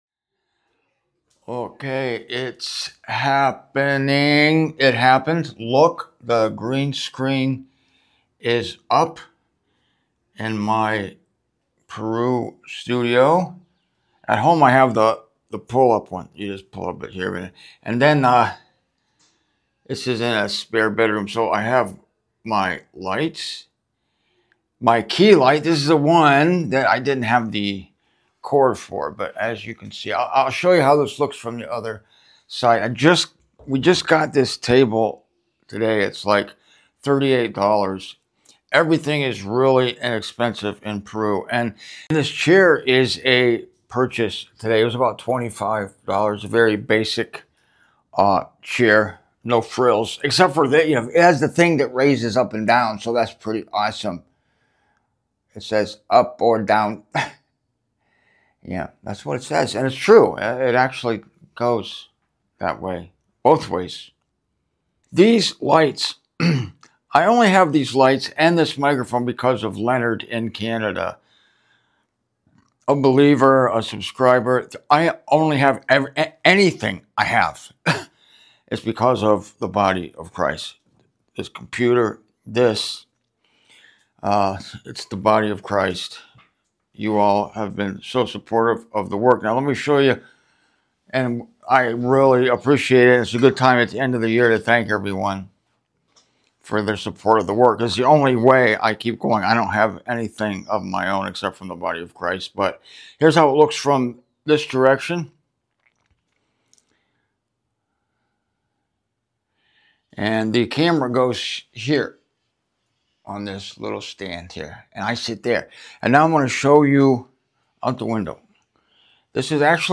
Also: a behind-the-scenes look at my "studio" here in Lima, Peru.